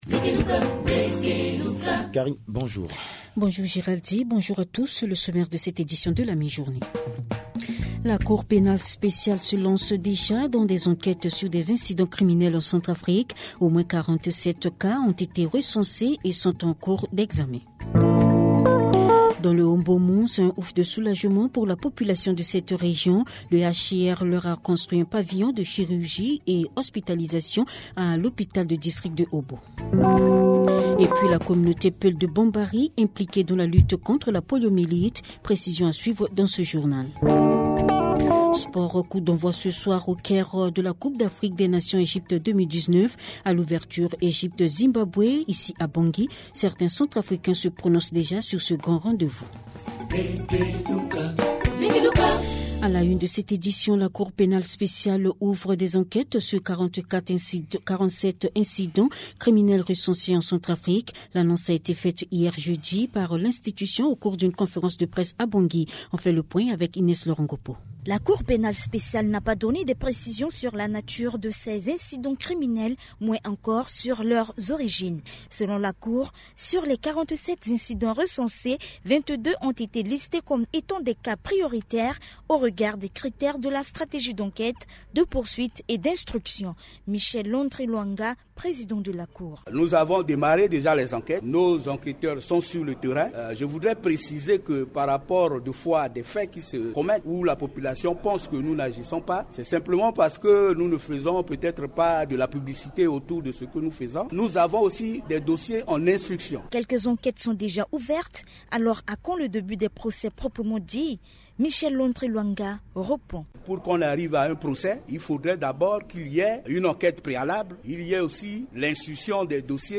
Journal Français